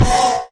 sounds / mob / horse / skeleton / hit1.mp3
hit1.mp3